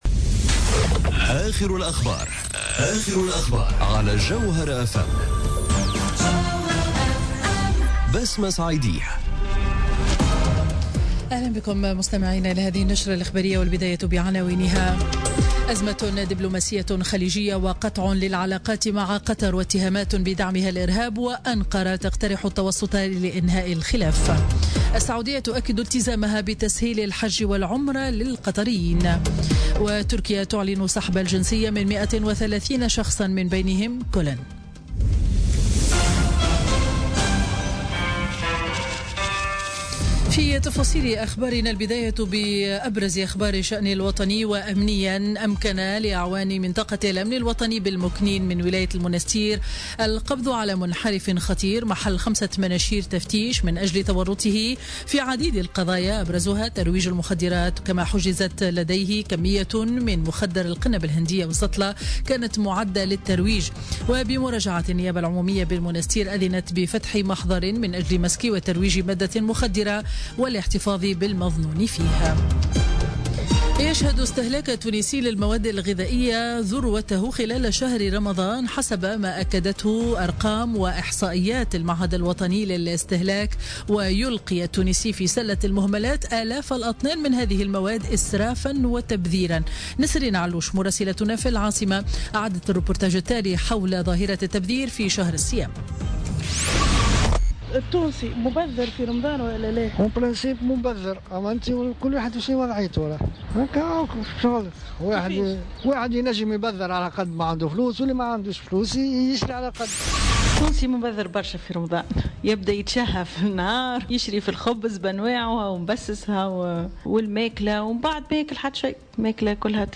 نشرة أخبار منتصف النهار ليوم الإثنين 5 جوان 2017